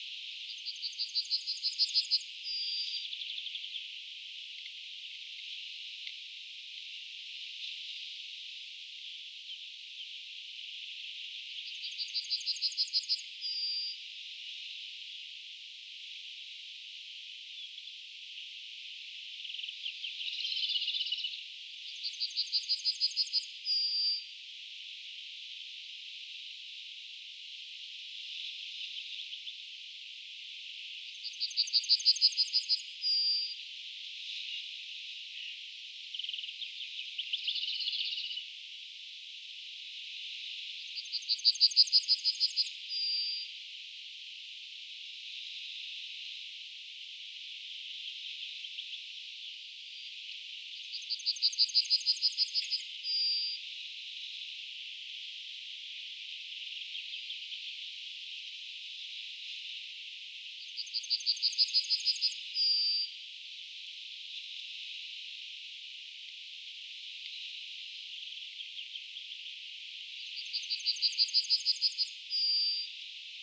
3. Sharp-Shinned Hawk (Accipiter striatus)
• Call: High-pitched rapid “kik-kik-kik”: